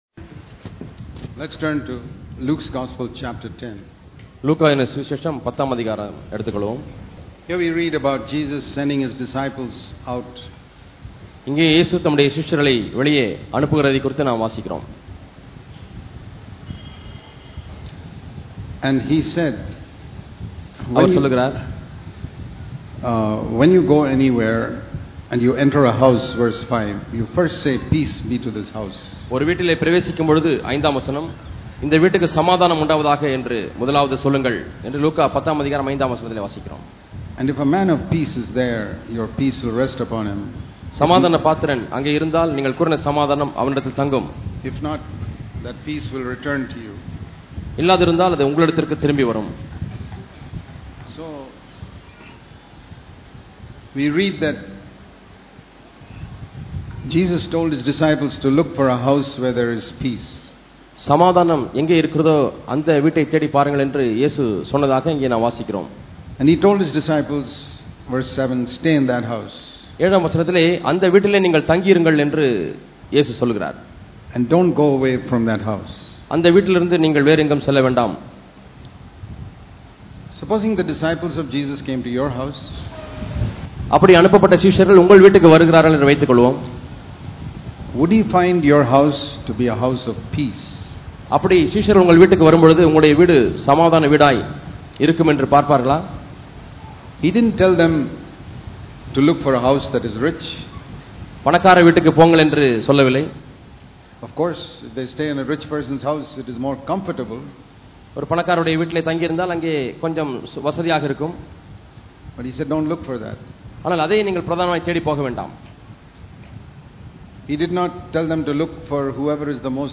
Making Our The Home A The Home Of Peace Holiness and Fellowship Click here to View All Sermons இத்தொடரின் செய்திகள் நான் பாவத்தின் கொடுமையை பார்த்துள்ளேனா?